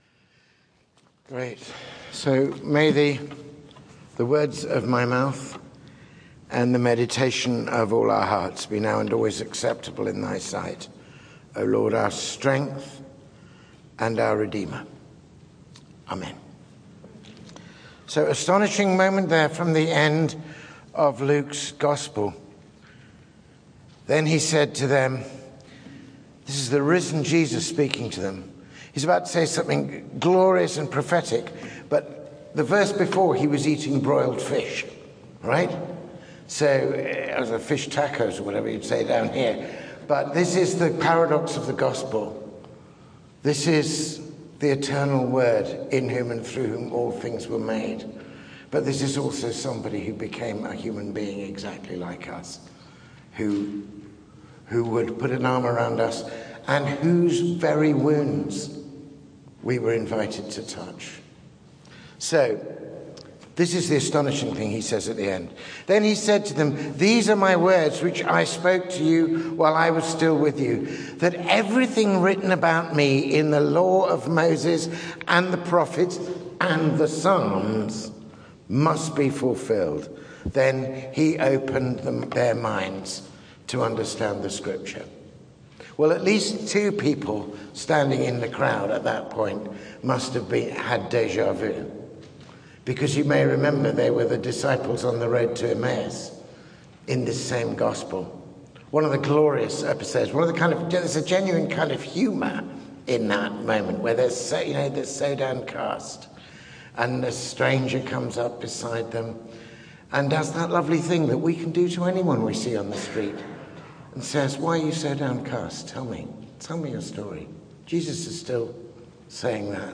Sermon 2/6: Christ in the Psalms